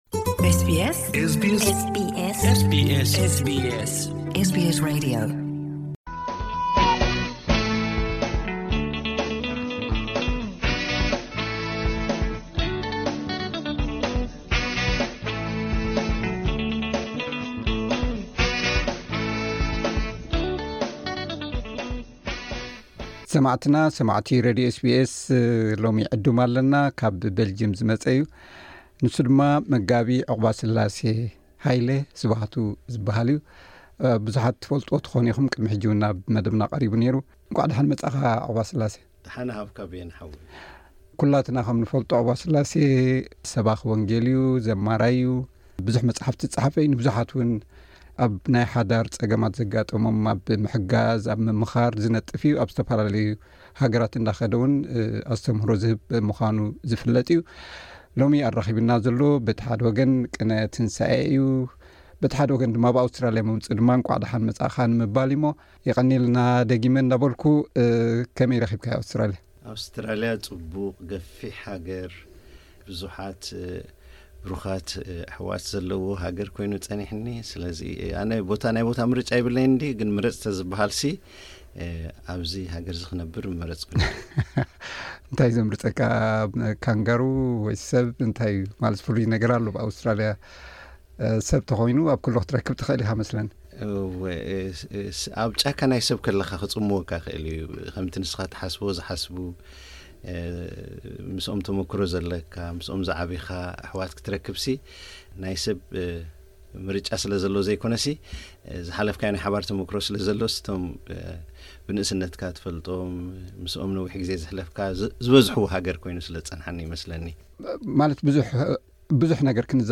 ቅነ ፋሲካን ኣብ ኣውስትራልያ ምምጽኡን ምኽንያት ብምግባር ኣብ’ዚ ኣብ ስቱድዮ ኤስቢኤስ ዓዲምና ዕላል ኣካይድናሉ’ለና።